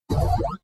满足的哔哔声